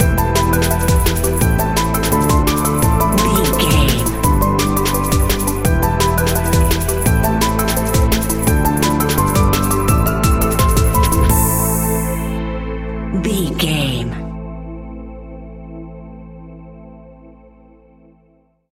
Aeolian/Minor
Fast
frantic
driving
energetic
hypnotic
dark
drum machine
electric piano
synthesiser
sub bass
instrumentals